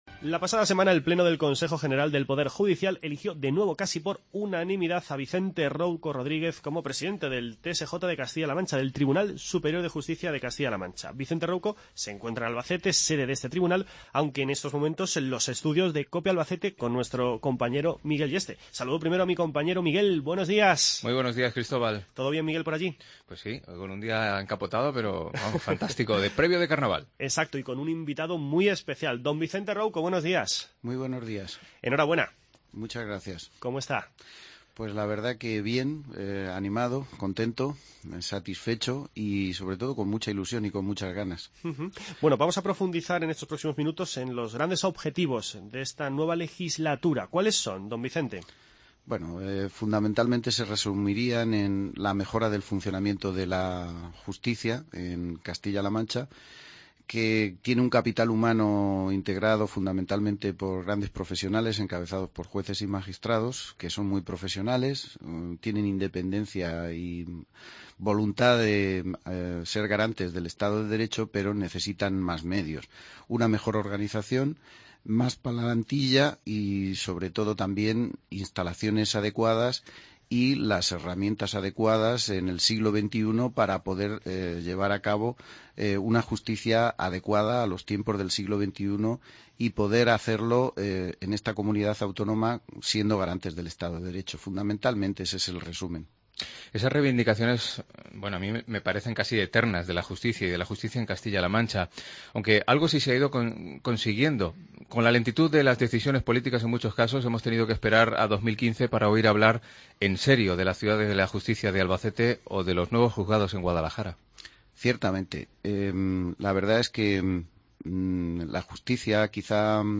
Escuche las entrevistas con Vicente Rouco, presidente del Tribunal Superior de Justicia de Castilla-La Mancha, y con Rodrigo Gutiérrez, director general de Calidad y Humanización de la Asistencia Sanitaria.